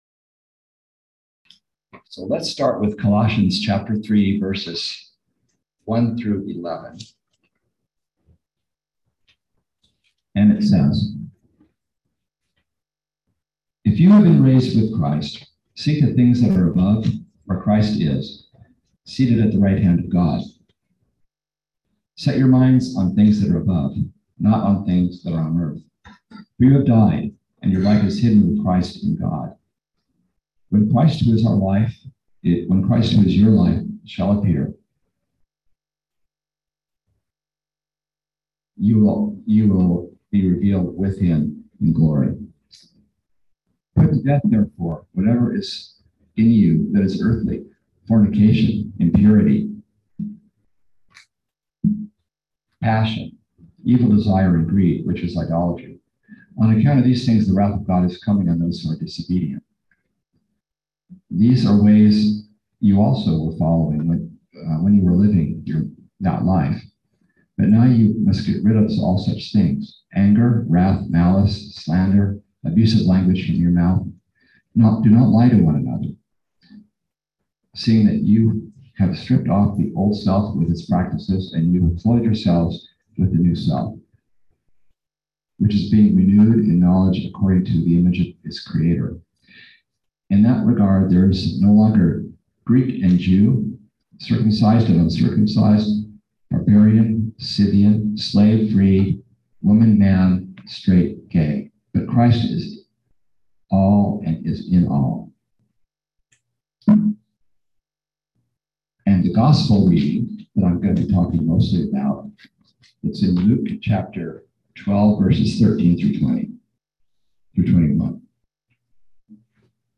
Listen to the most recent message from Sunday worship at Berkeley Friends Church, “Rich toward God.”